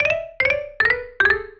《転がる》フリー効果音
どんぐりが転がる、転ける、転げ落ちるような効果音。マリンバで可愛く。
rolling.mp3